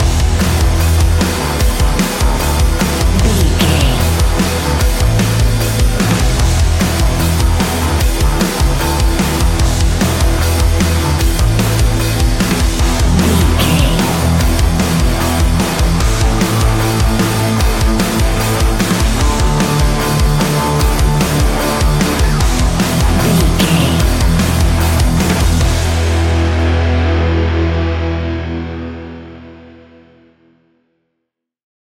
Ionian/Major
E♭
heavy metal